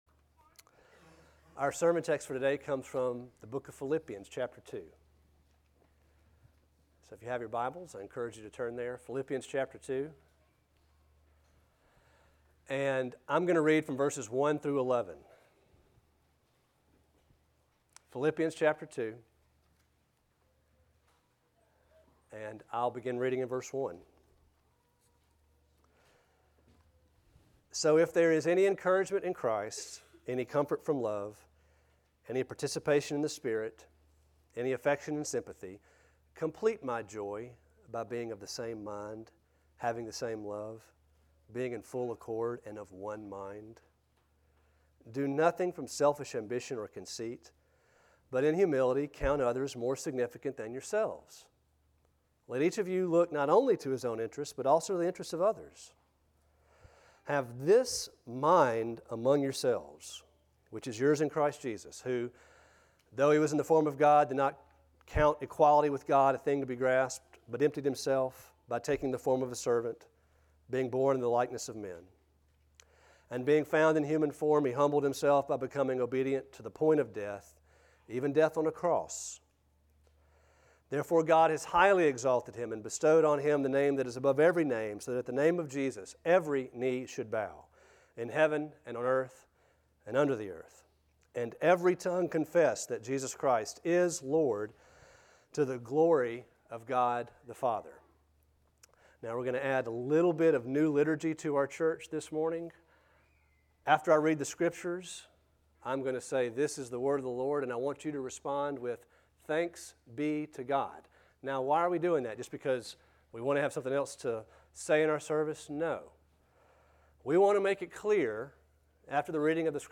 A sermon from the series "Have this Mind in You."